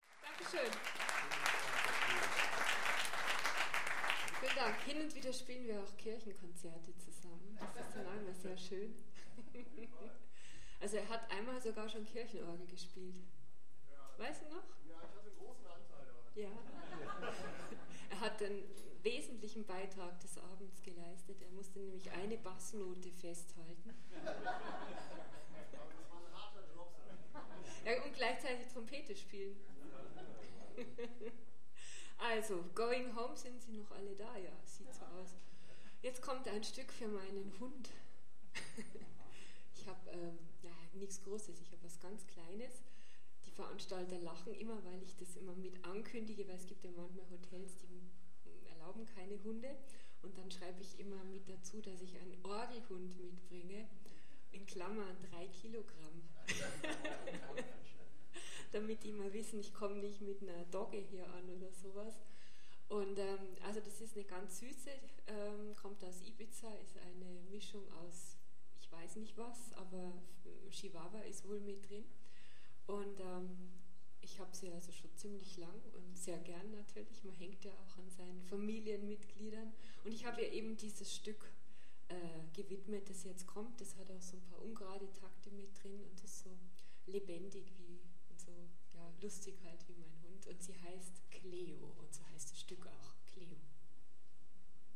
10 - Ansage.mp3